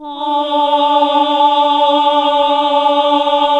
AAH D2 -R.wav